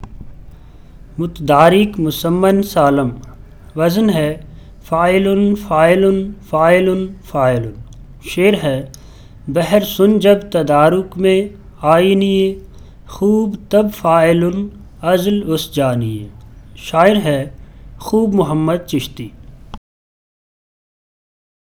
Audio samples of the recitation of the Urdu metre